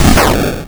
bakuhatu83.wav